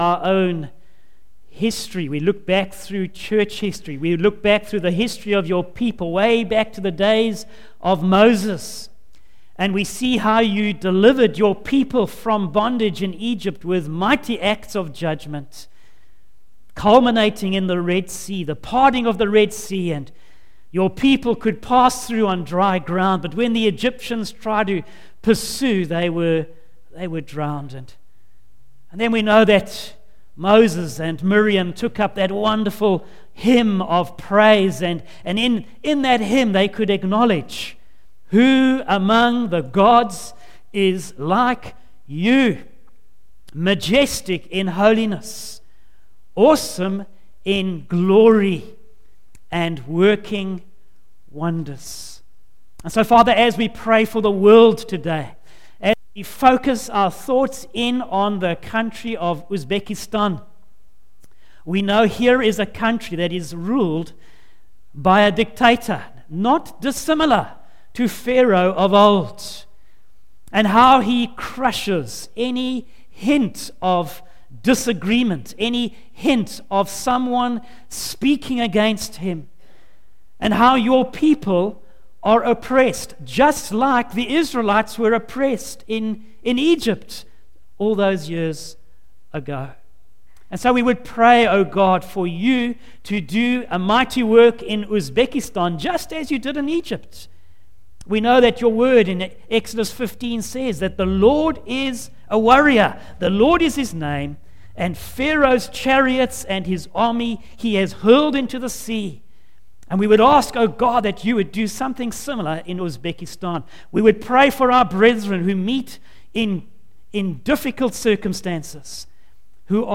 Facebook Twitter email Posted in Morning Service